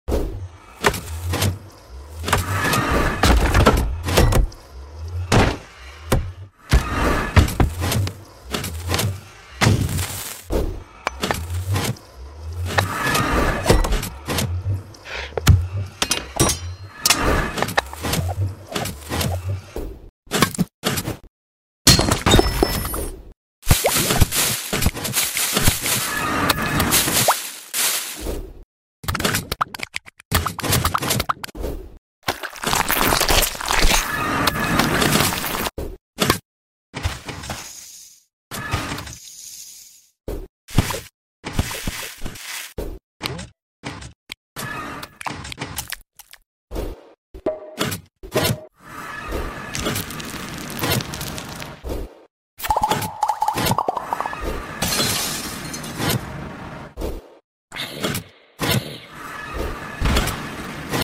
Minecraft Vs Real Life In Sound Effects Free Download